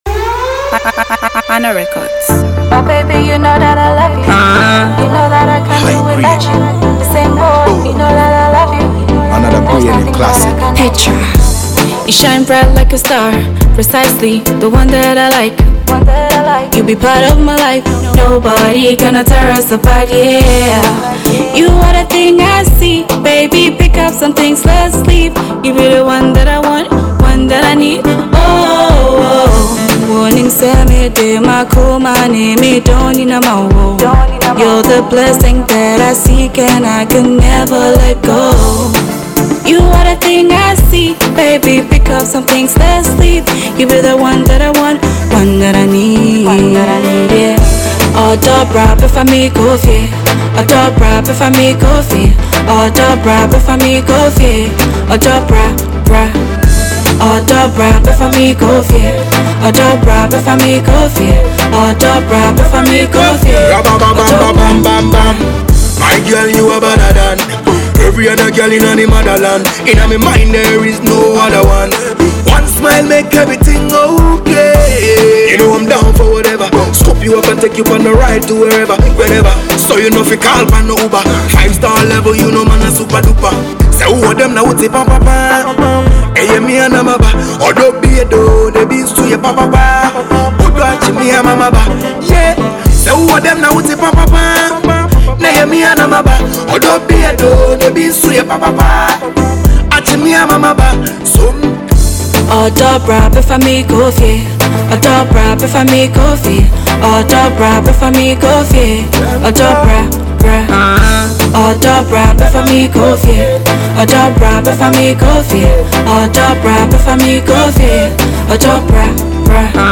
Ghanaian Songstress
Reggae/Dancehall